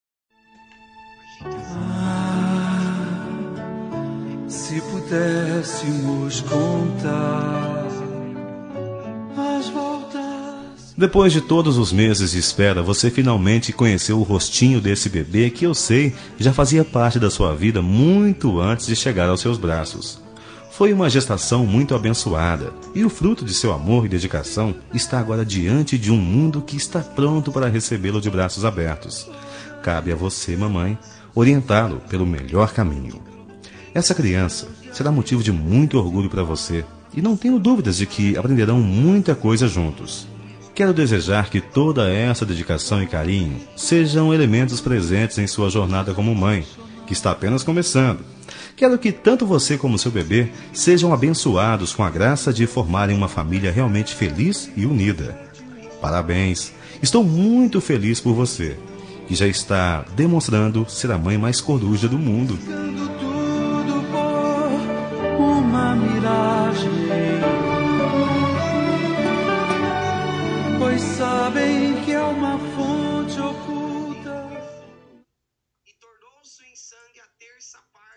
Telemensagem de Maternidade – Voz Masculina – Cód: 6636